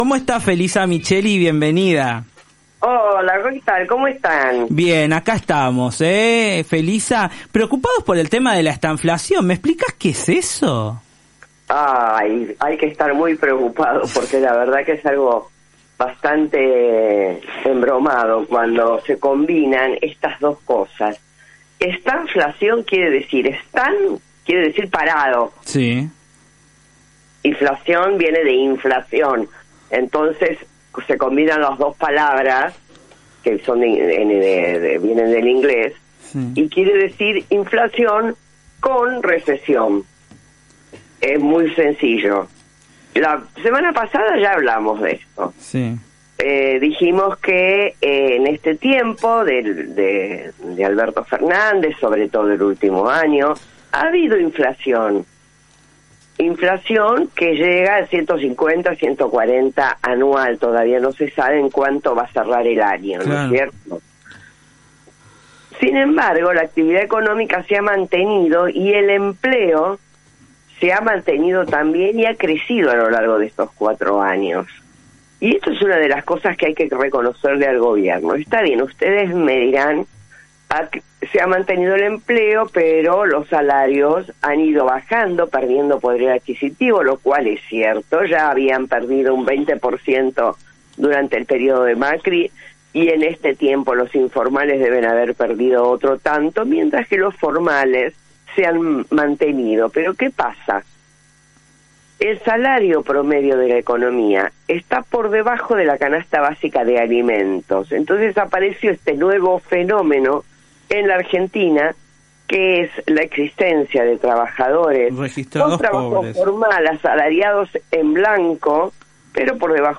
La ex Ministra de Economía en su columna semanal, explicó que significa estanflación, cual es la situación actual y la que vendrá de acuerdo a las medidas anunciadas. Le respondió al presidente saliente Alberto Fernández por sus dichos sobre la pobreza.